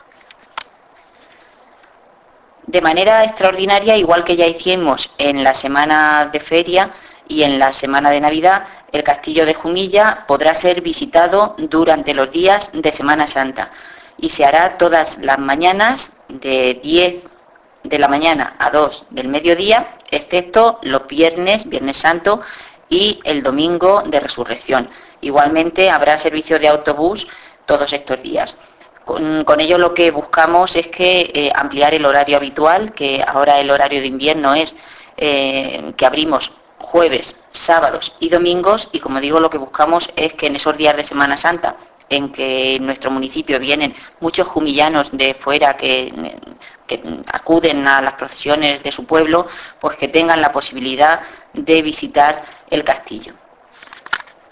Descargar: La concejal de Cultura y Turismo habla de esta apertura subir